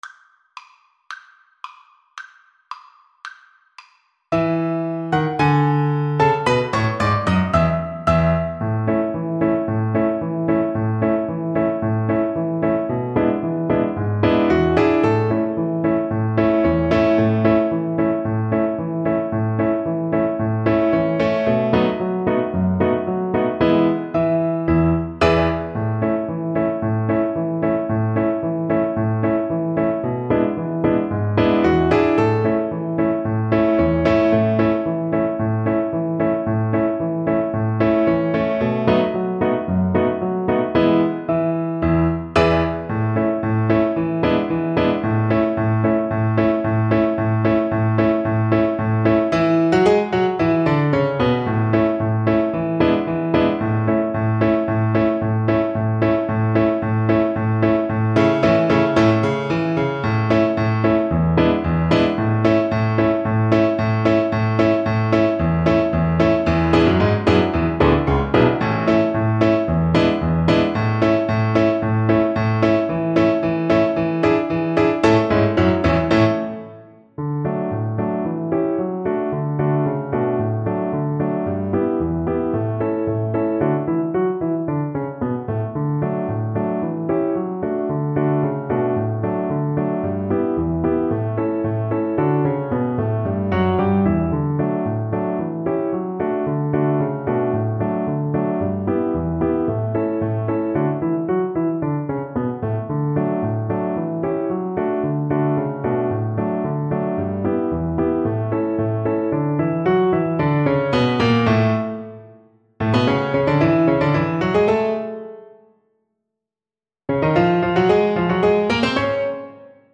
2/2 (View more 2/2 Music)
Alla Marcia = 112